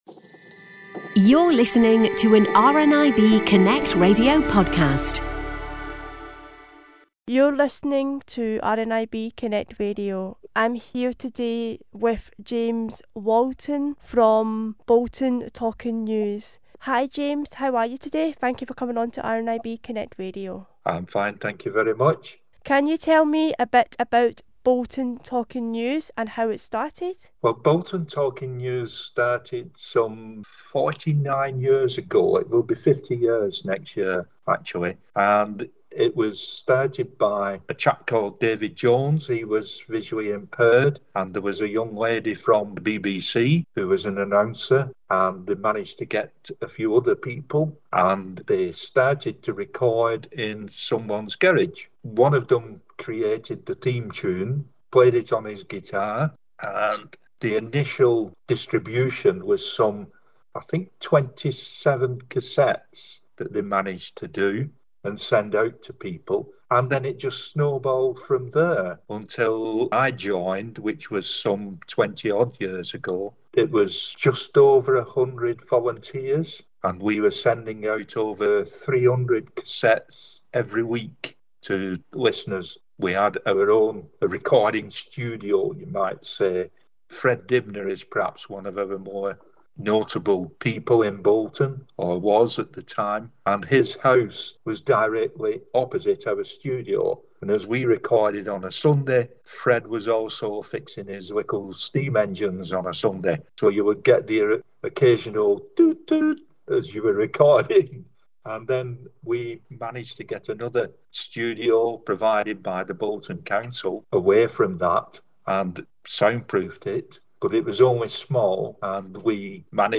Bolton Newstalk, Interview